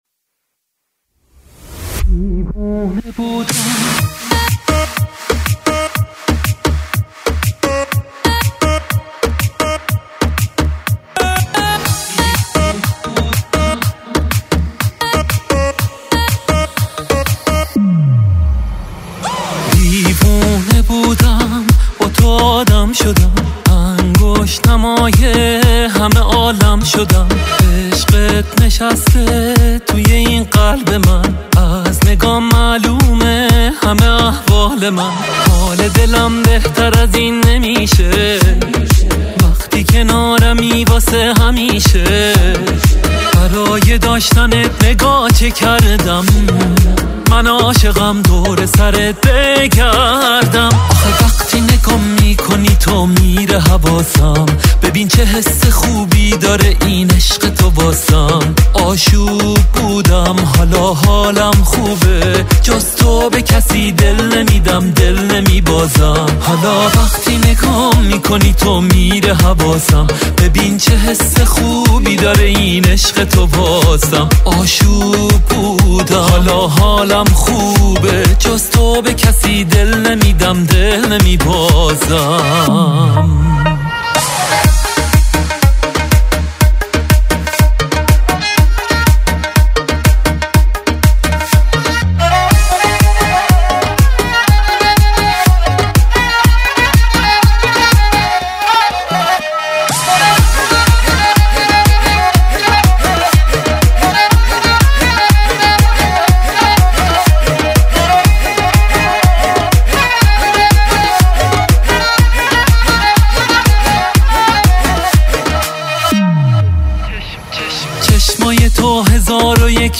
آهنگهای پاپ فارسی
خیلی قشنگ میخونه 👍